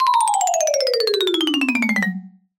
comedy_marimba_descend_002